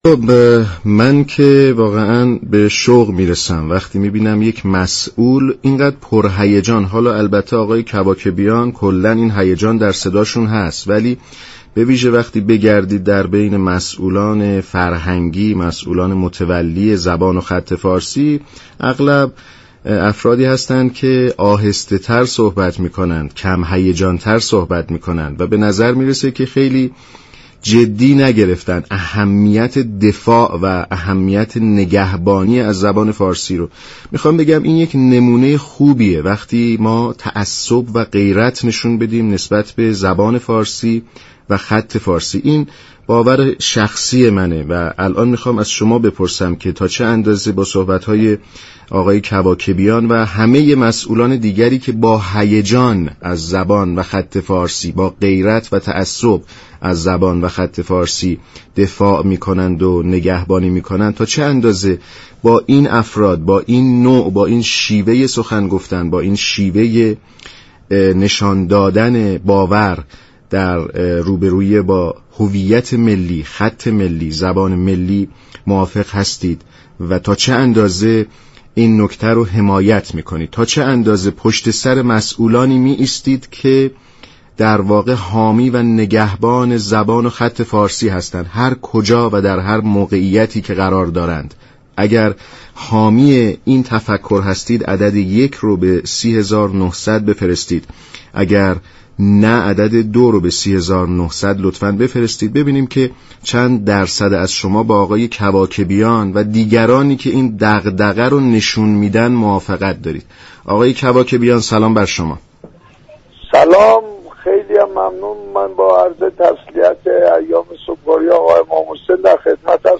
به گزارش شبكه رادیو ایران، برنامه كافه هنر برای بررسی بیشتر با مصطفی كواكبیان نماینده مردم تهران در مجلس شورای اسلامی در این خصوص گفت و گو كرده است.